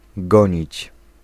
Ääntäminen
Tuntematon aksentti: IPA: /puʁ.sɥivʁ/